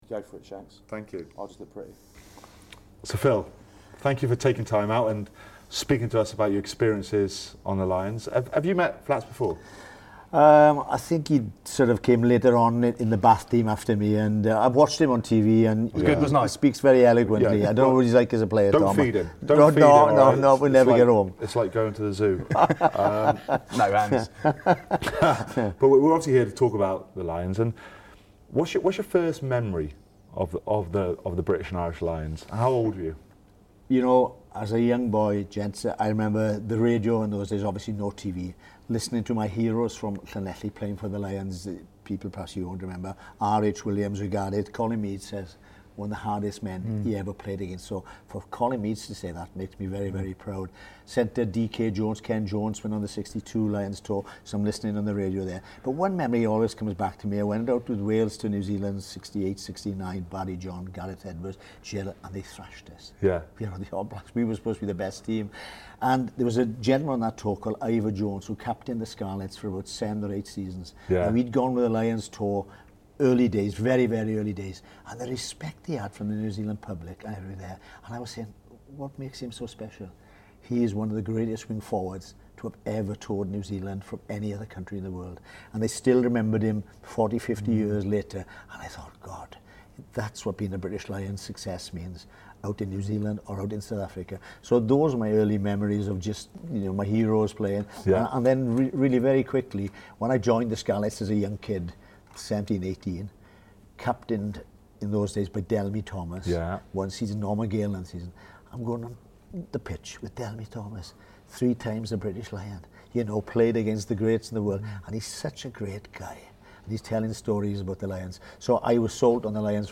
Incredible interview with Phil Bennett MBE about his experiences with the British & Irish Lions in 74 and 77